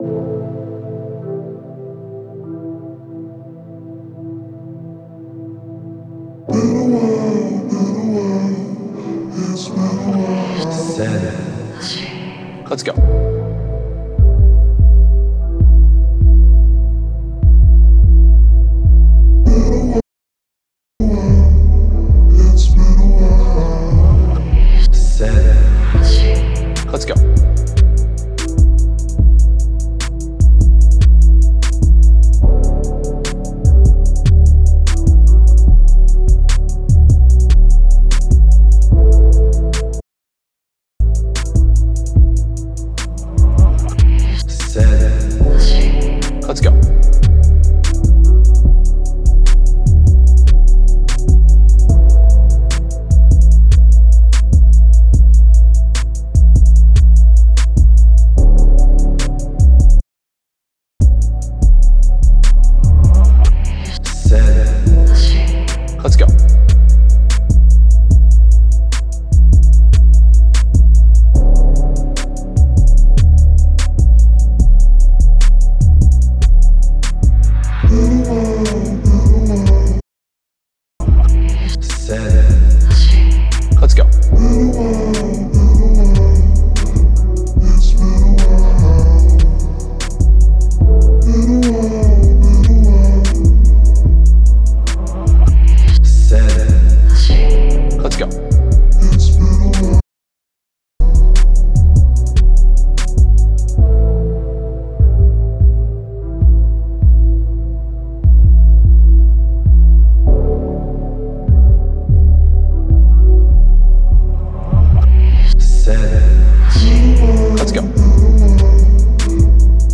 Dreamlike – R&B – Vocals – Type Beat
Key: A Minor
148 BPM